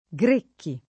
[ g r % kki o g r $ kki ]